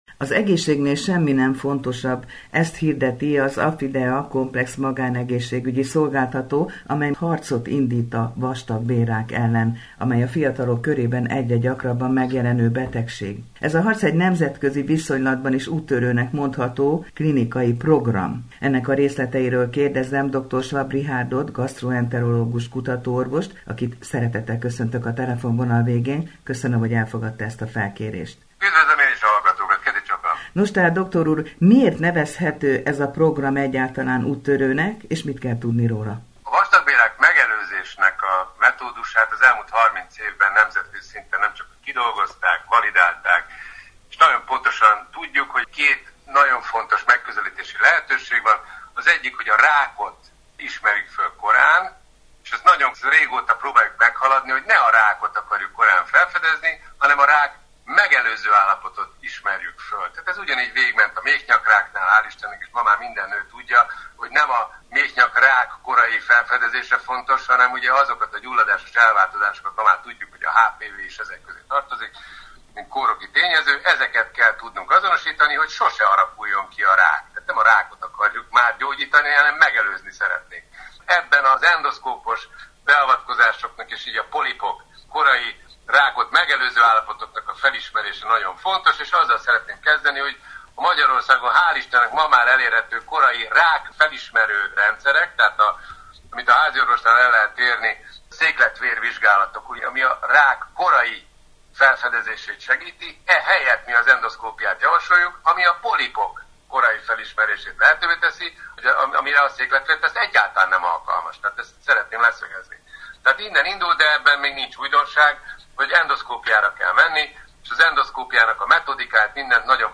Harc a vastagbélrák ellen - Interjú